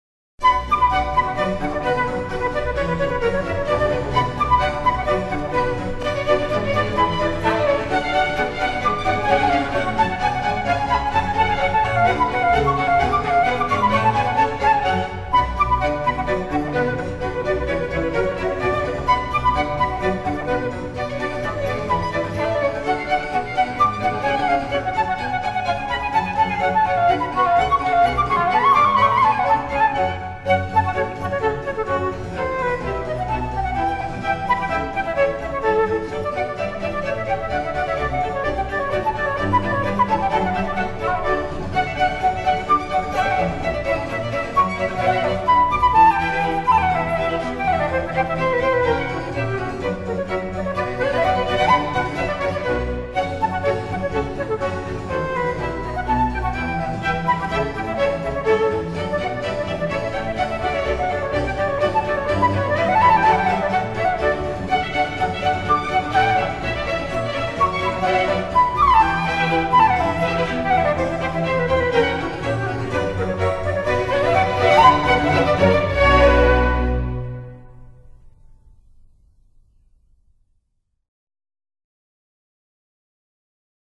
J. S. Bachas. Siuta orkestrui Nr. 2. Badinerie